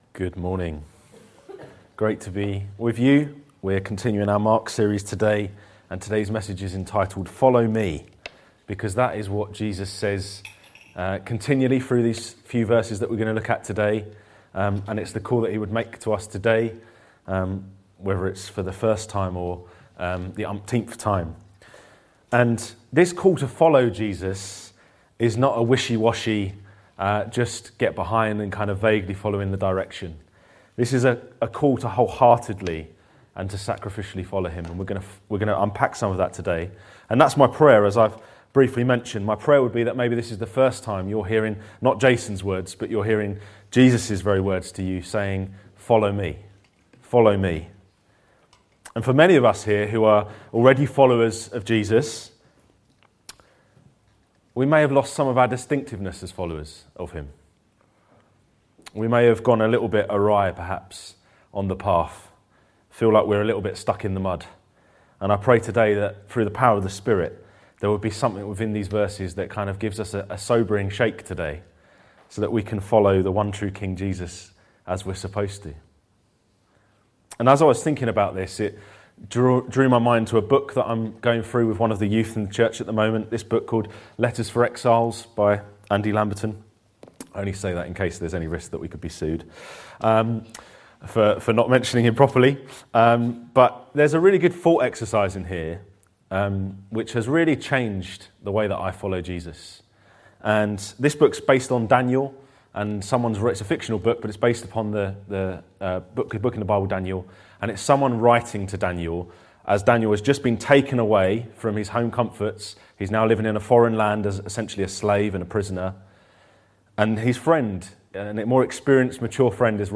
This sermon focuses on Jesus’ call at the beginning of his ministry to follow him.